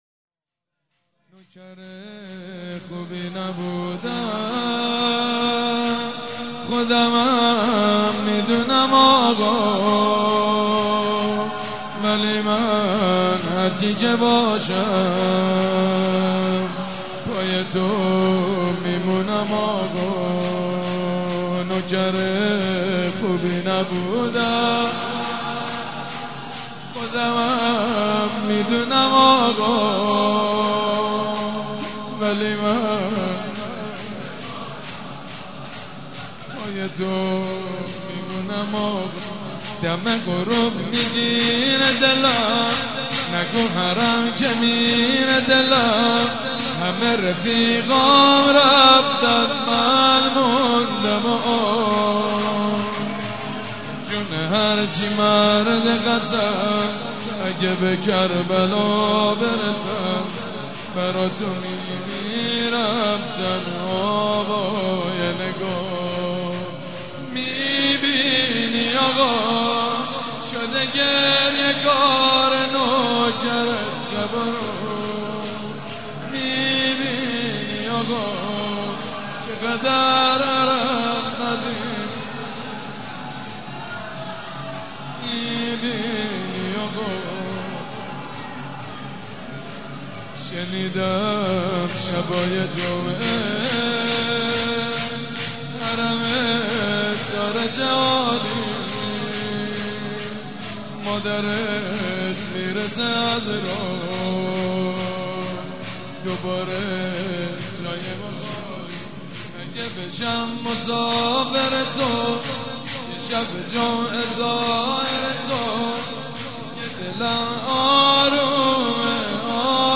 شب پنجم فاطميه دوم١٣٩٤
شور
مداح
حاج سید مجید بنی فاطمه
مراسم عزاداری شب شهادت حضرت زهرا (س)